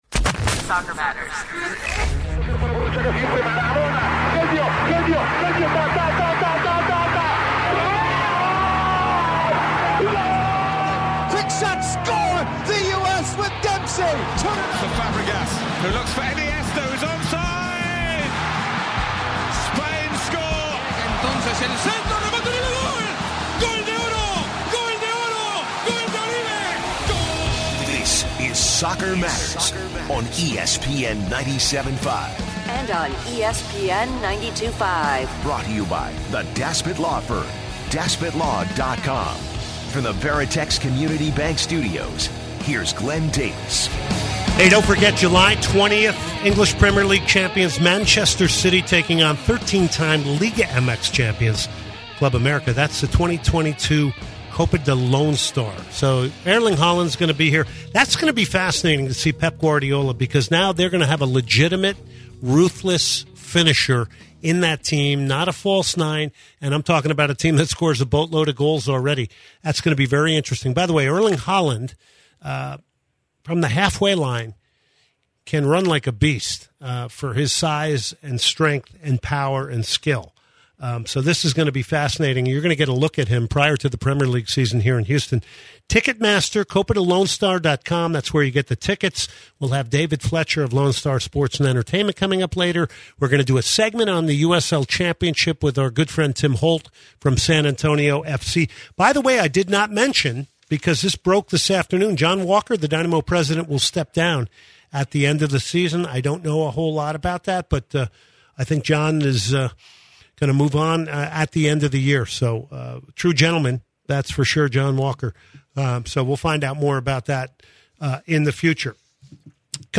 Moving onto World Cup Qualification as Australia and Costa Rica advance to the major tournament. Ending off the show with interviews